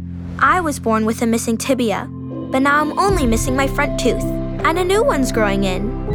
Commercial (Ste-Justine) - EN